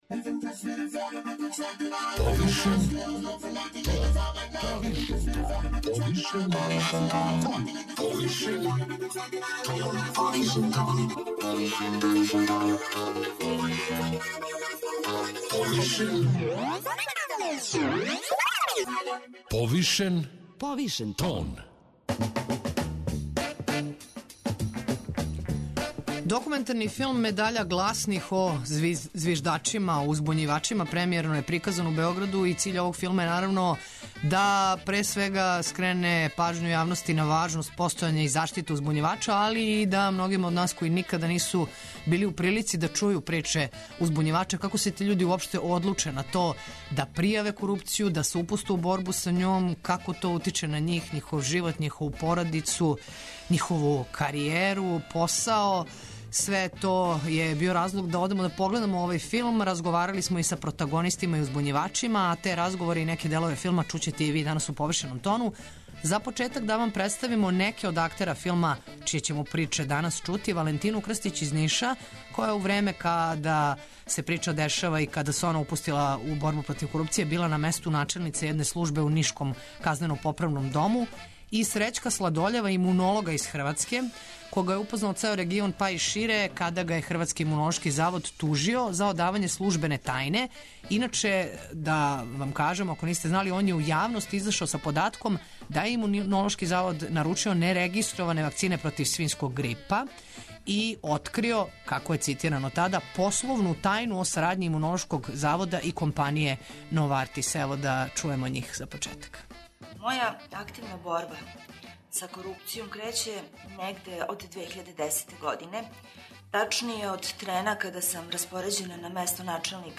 Ми смо погледали филм и разговарали са протагонистима и узбуњивачима а те разговоре и неке делове филма чућете и ви у "Повишеном тону".